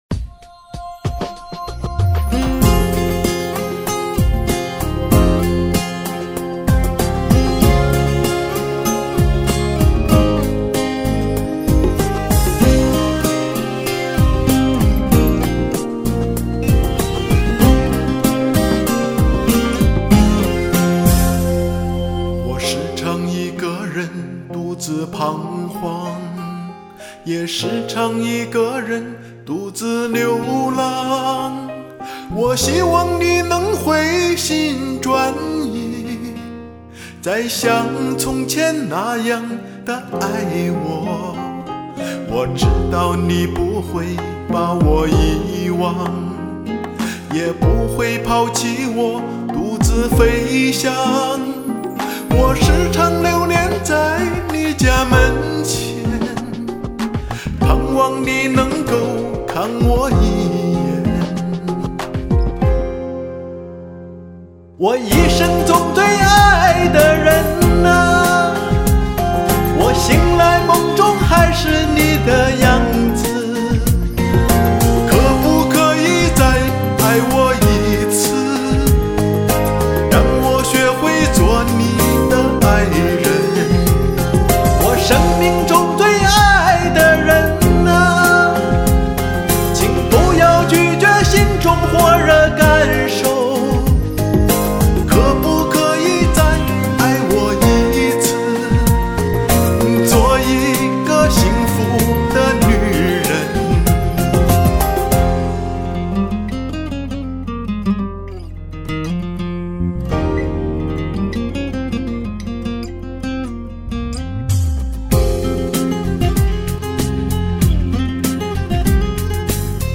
新版的歌效果太棒啦，真是完美啊！
喜欢你唱歌的投入...感动